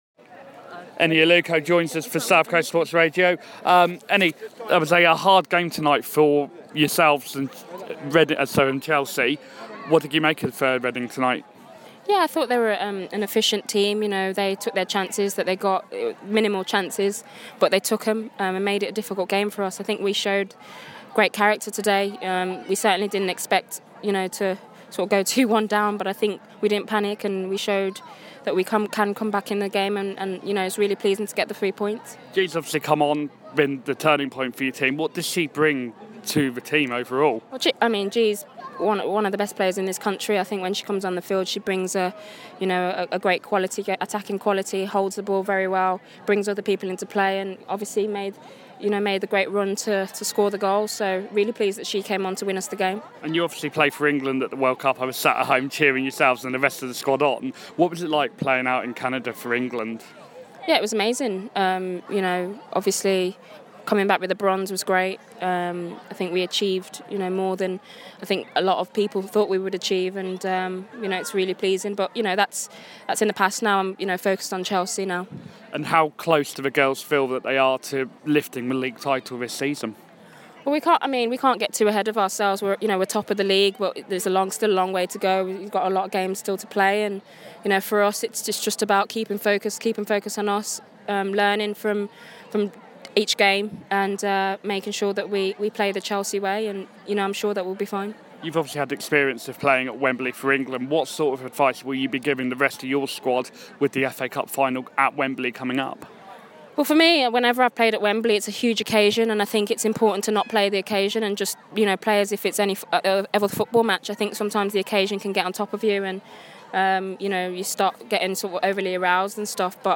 Eni Aluko interview